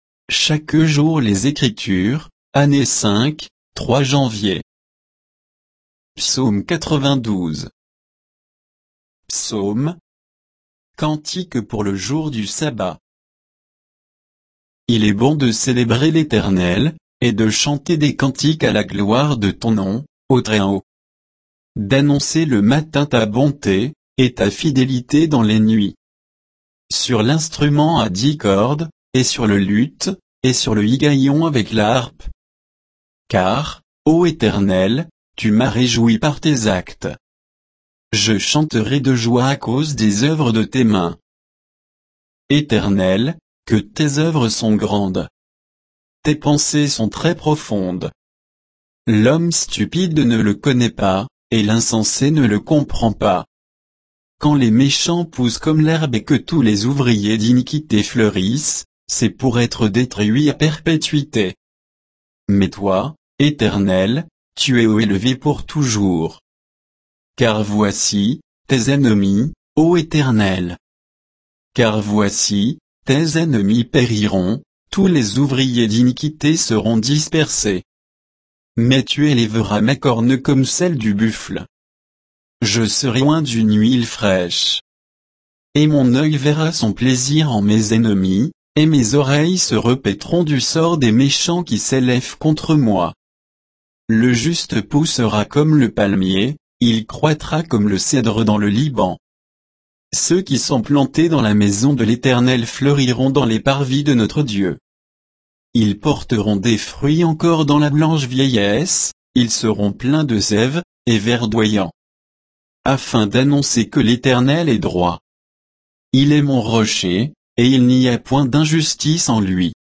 Méditation quoditienne de Chaque jour les Écritures sur Psaumes 92 et 93